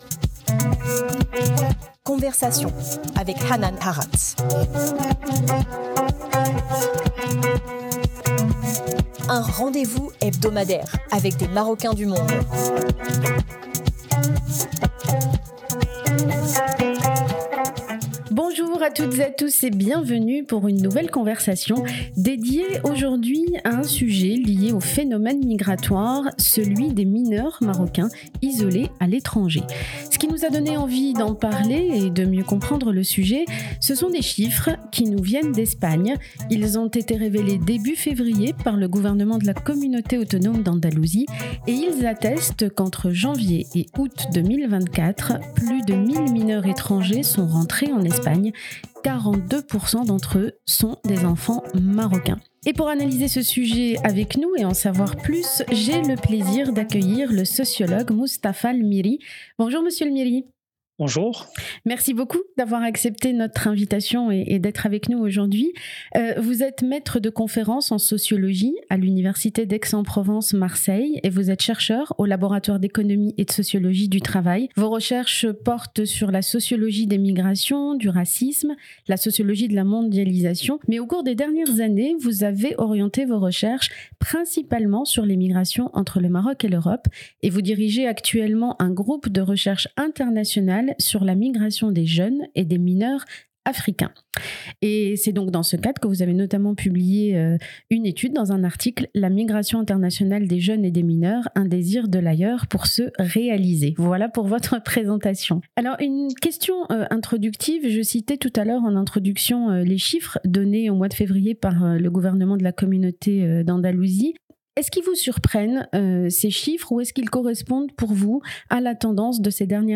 sociologue (France)